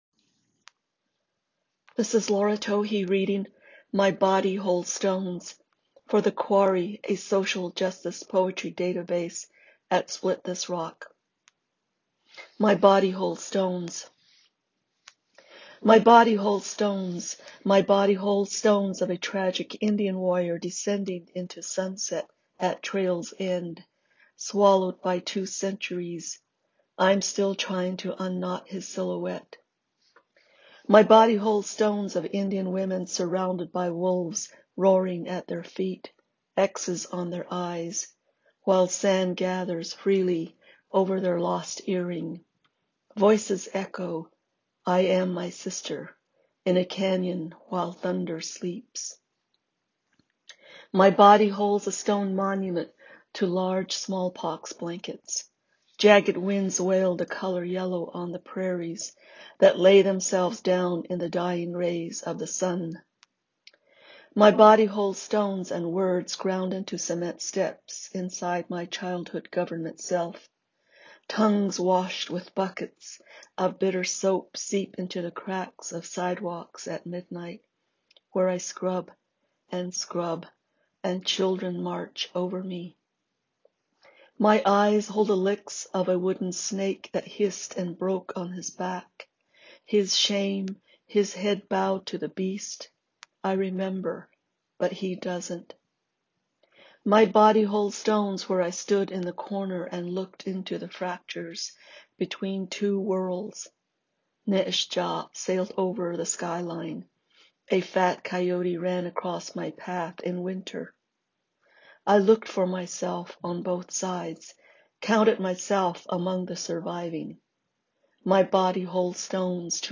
Listen as Laura Tohe reads "My Body Holds Stones".